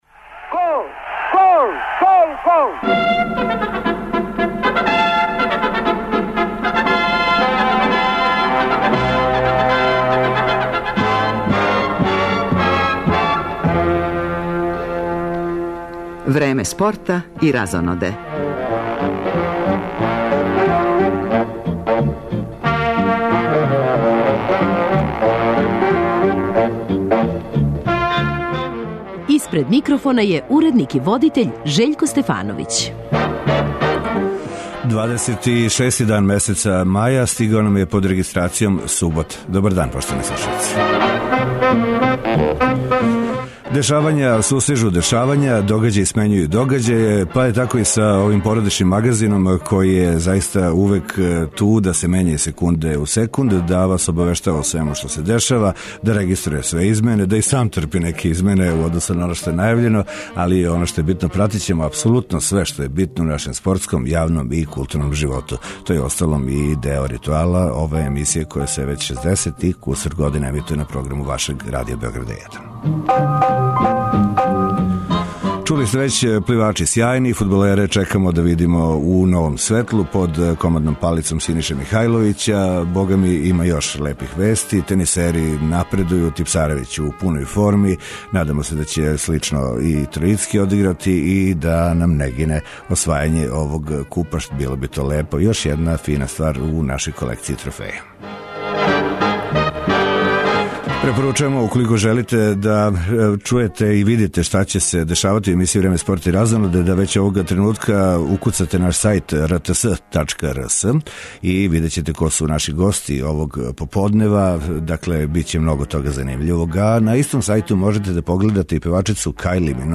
Фудбалска репрезентација Србије има ватрено крштење под вођством новог селектора Синише Михајловића - пратићемо кретање резултата на пријатељској утакмици против Шпаније, која се игра у Сент Галену. Чућемо и изјаве савезног капитена и репрезентативаца наше земље.